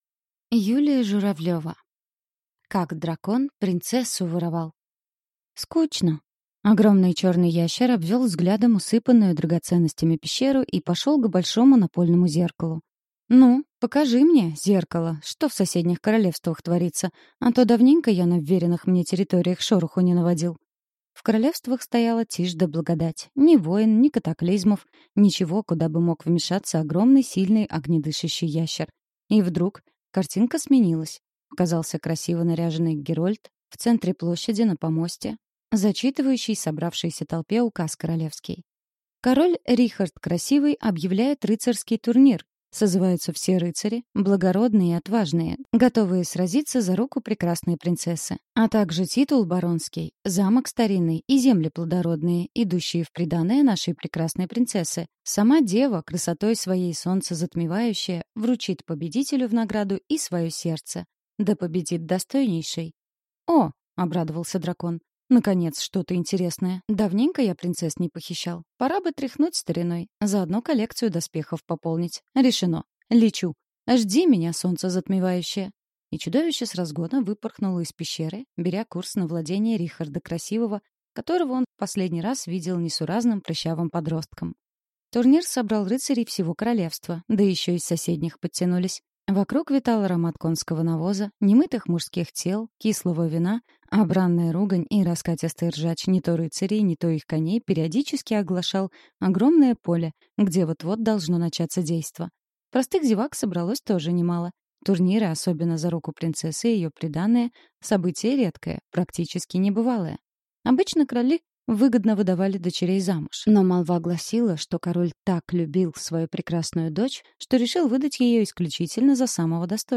Аудиокнига Как дракон принцессу воровал | Библиотека аудиокниг